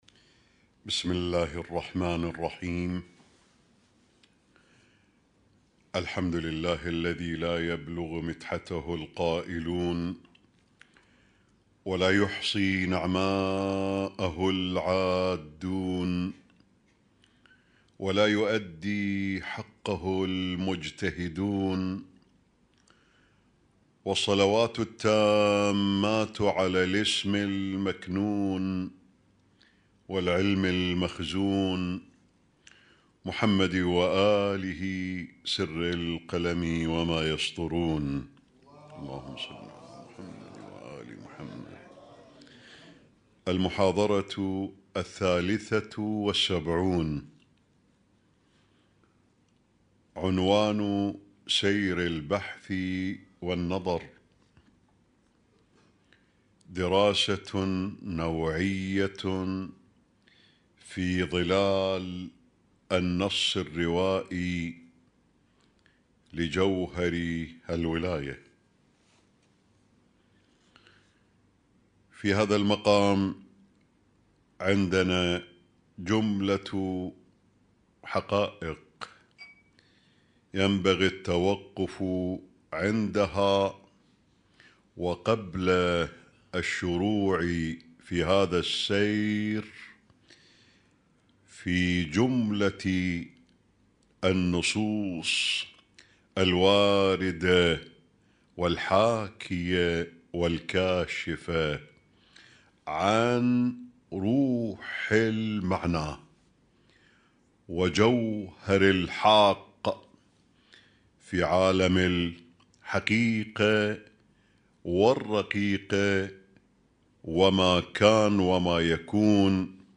Husainyt Alnoor Rumaithiya Kuwait
اسم التصنيف: المـكتبة الصــوتيه >> الدروس الصوتية >> الرؤية المعرفية الهادفة